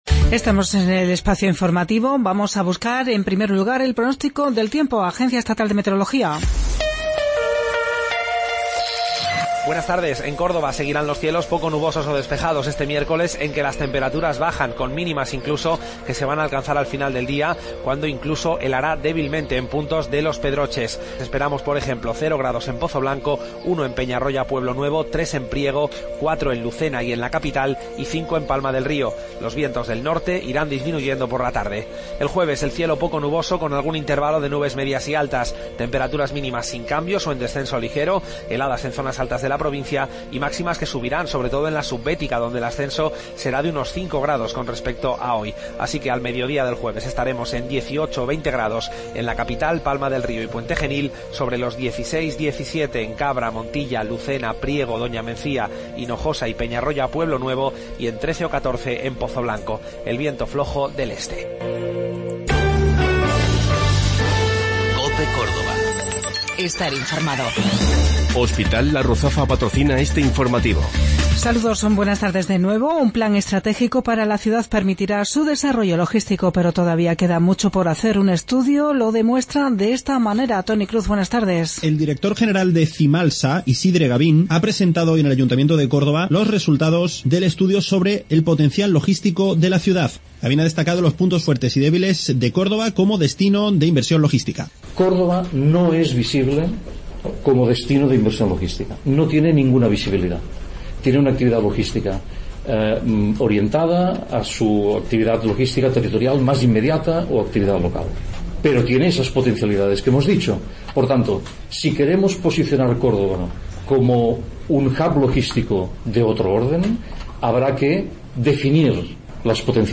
Mediodía en Cope. Informativo local 8 de Febrero 2017